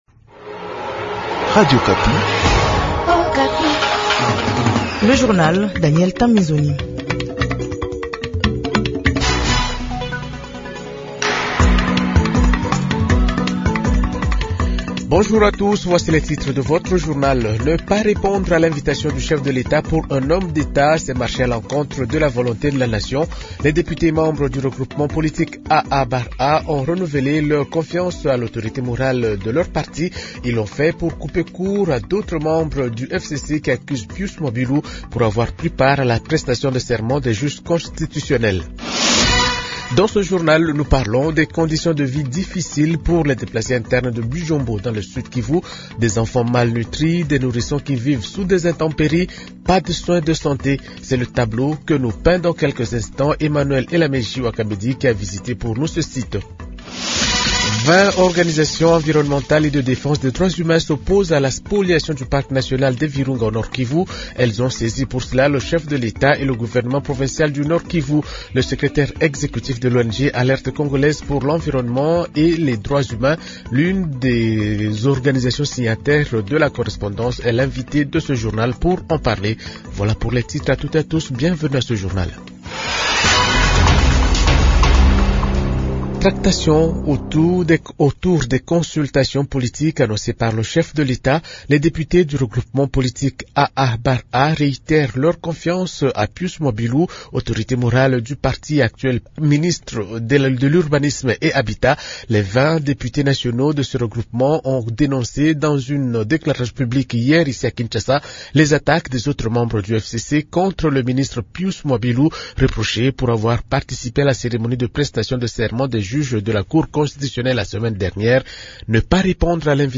Journal Francais Matin 6h00